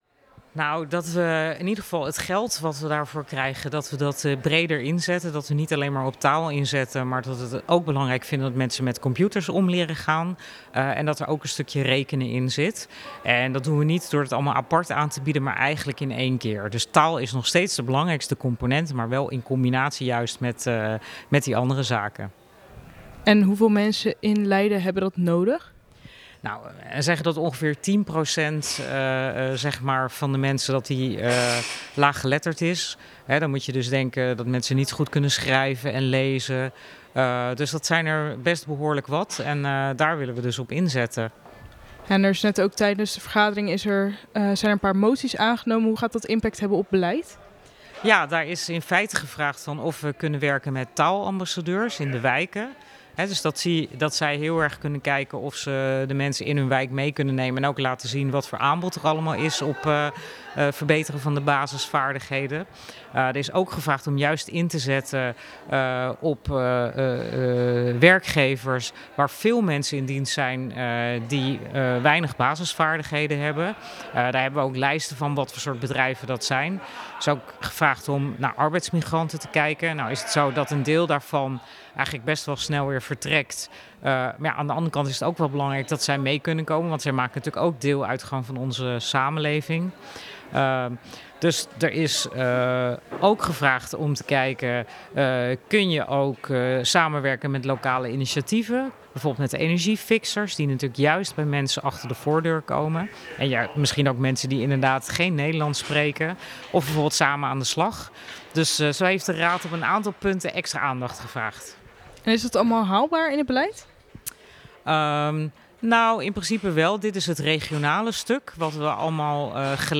spreekt met wethouder Yvonne van Delft over de verandering in het beleid en de toevoegingen